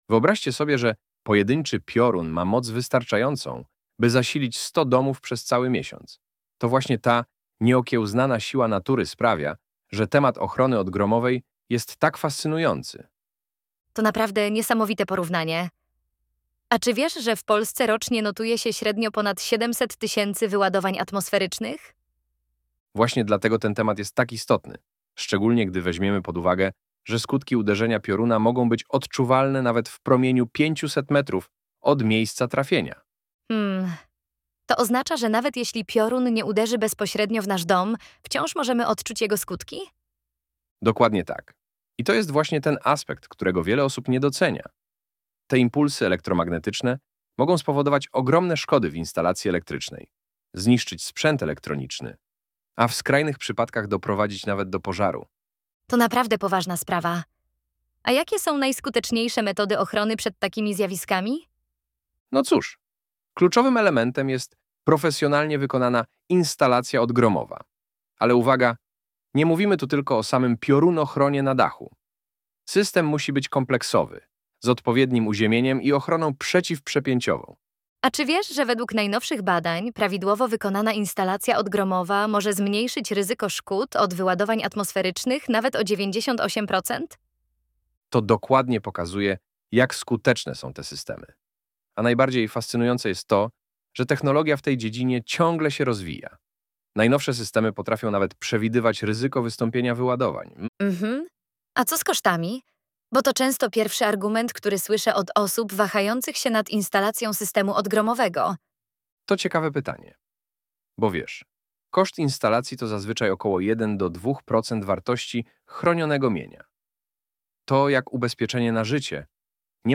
Poznajcie naszych wirtualnych inżynierów Chrisa i Jessicę , którzy opowiedzą wam wiele ciekawych historii w poniższych podcastach 😃